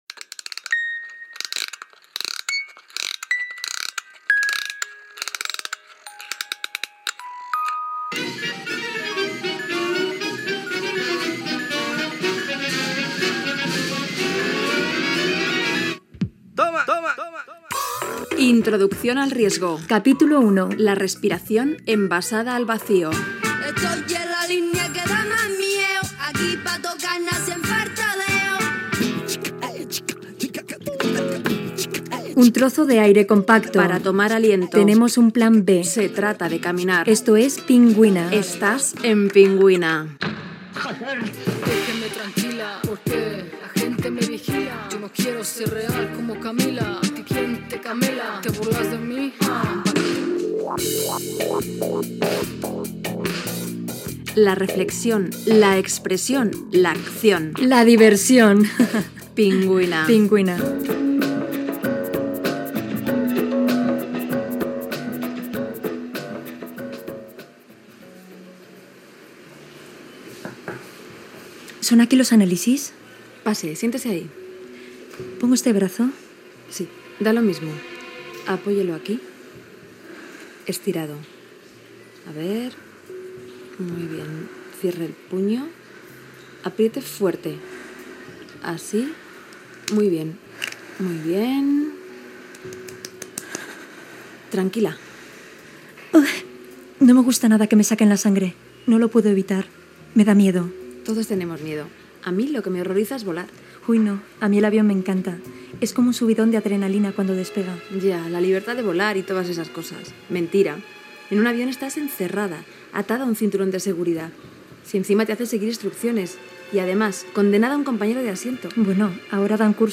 Careta del programa, espai sobre feminisme, el risc i la necessitat de moure's
Presentador/a
Extret del CD Open Radio 2002, celebrat al CCCB.